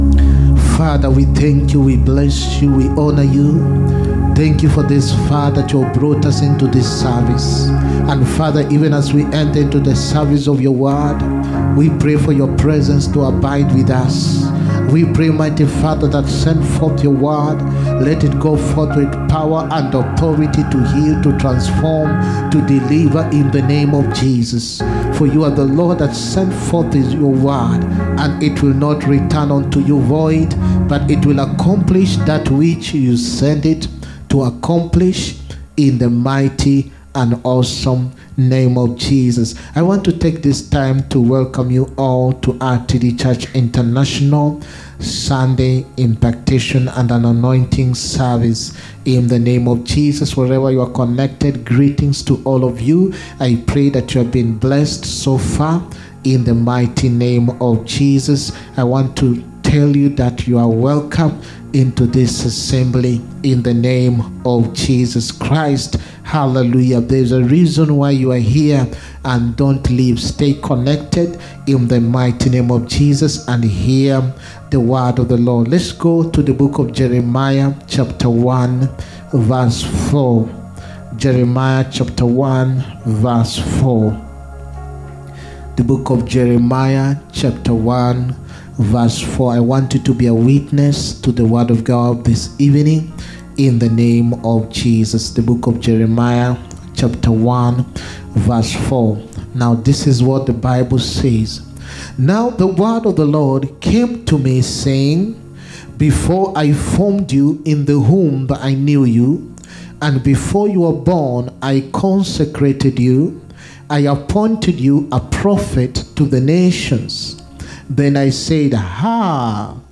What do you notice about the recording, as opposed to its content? SUNDAY ANOINTING AND IMPACTATION SERVICE. 17TH DECEMBER 2023.